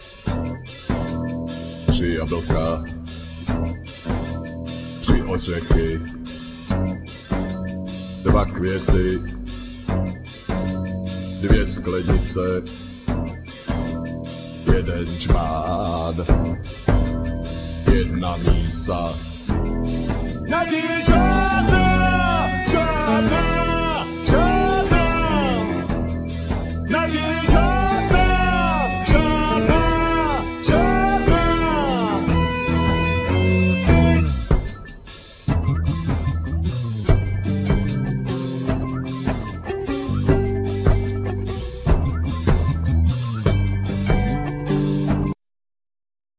Vocals,Saxes,Guitars,Keyboards,Sirens
Drums,Percussions,Groove box,Electronics
Bass,Didgeridoo